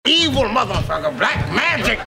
Soundboard